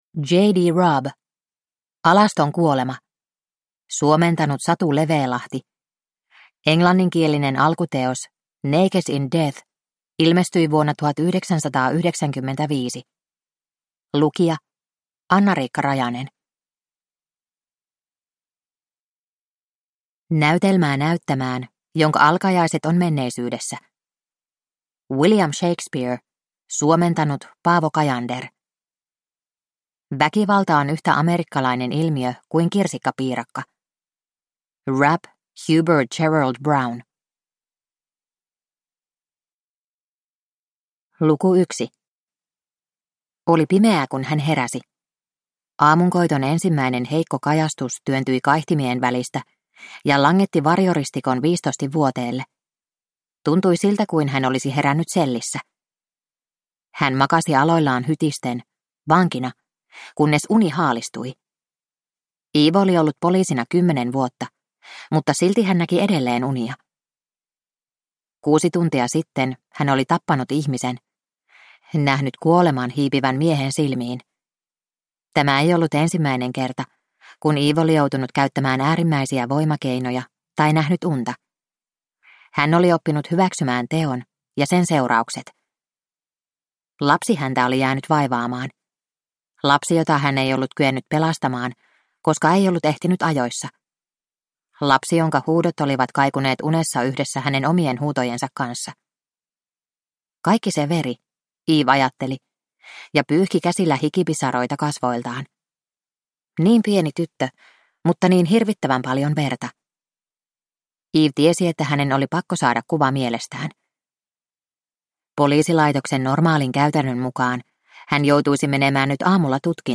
Alaston kuolema – Ljudbok